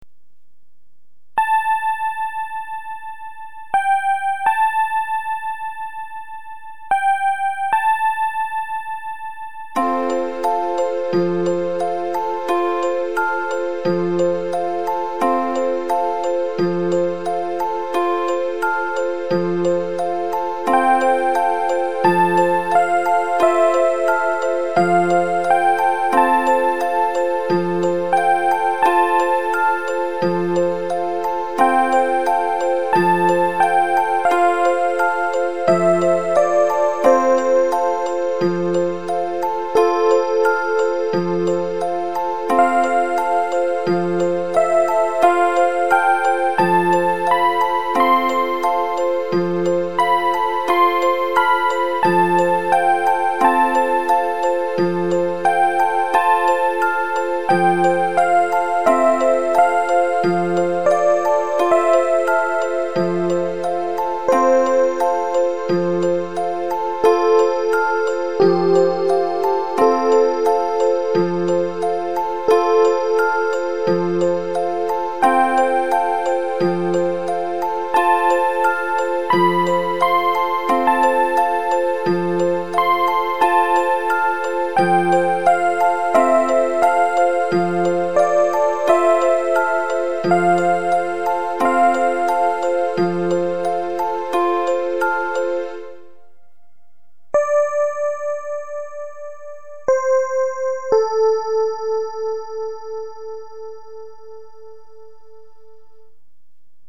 a song I make with my organ, it has a programed sound to